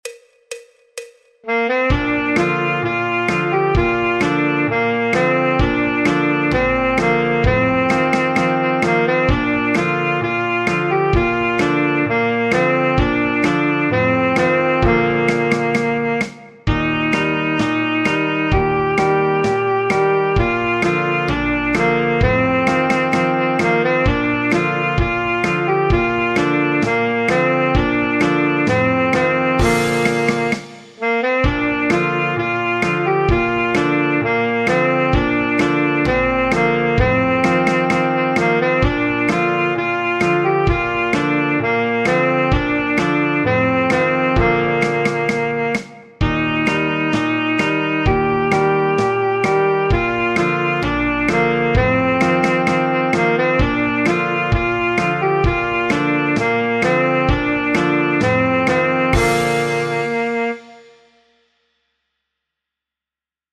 El MIDI tiene la base instrumental de acompañamiento.
Saxofón Alto / Saxo Barítono
Folk, Popular/Tradicional